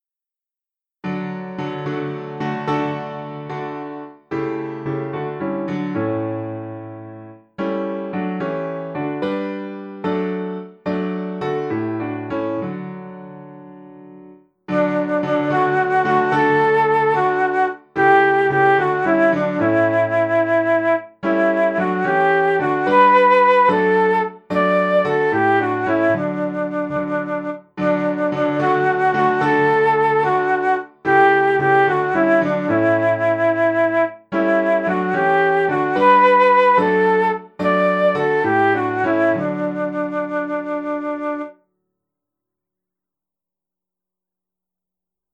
melody traditional German .